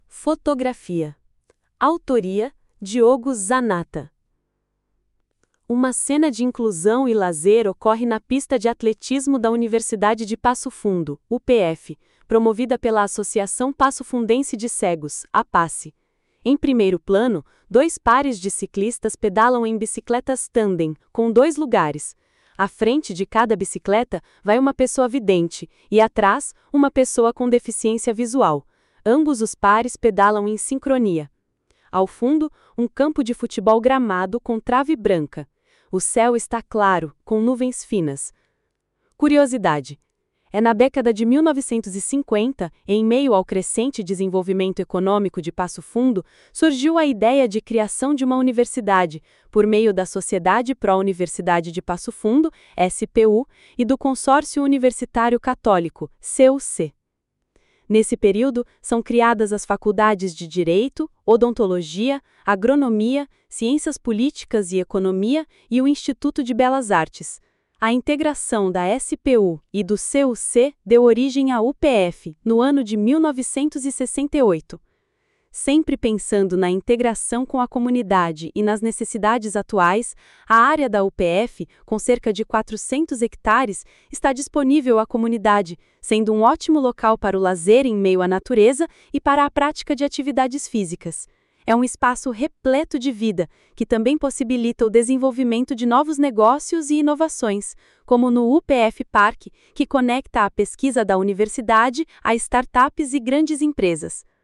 Audioguia
Audiodescrição